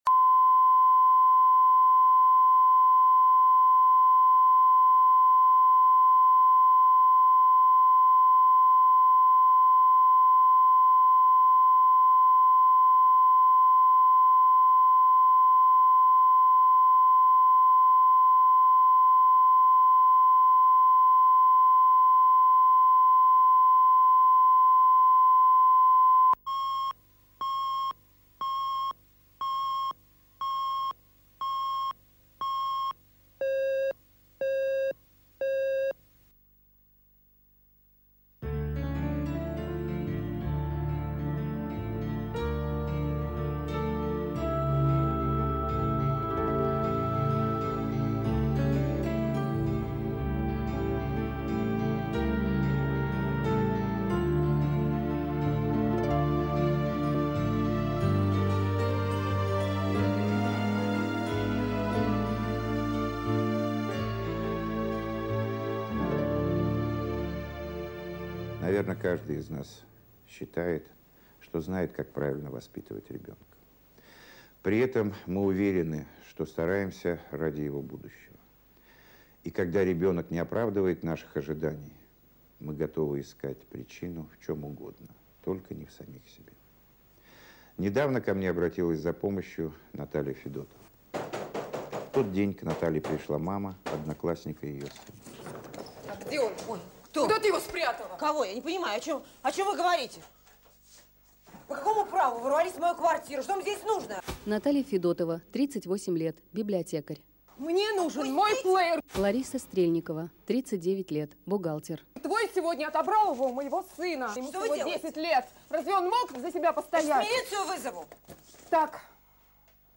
Аудиокнига Трудный возраст | Библиотека аудиокниг